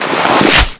backpack_ammo.wav